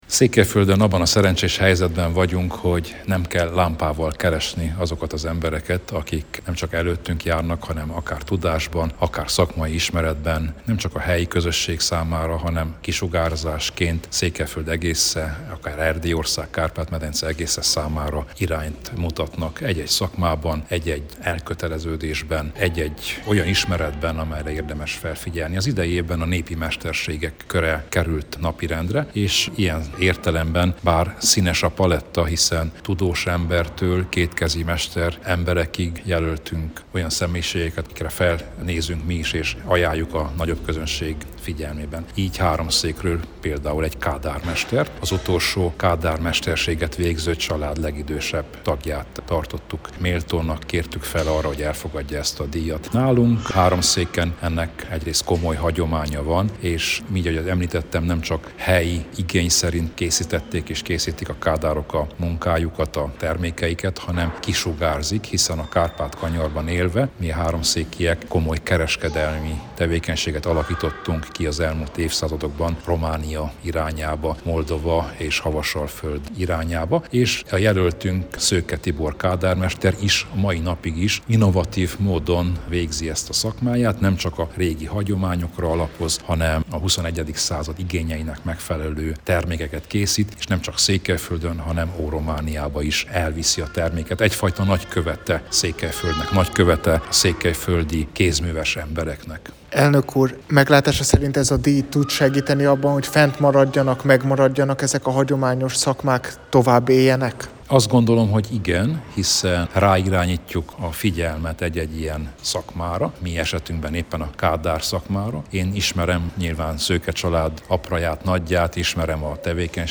Az ünnepélyes díjátadóra október 1-én került sor Marosvásárhelyen, a Kultúrpalotában.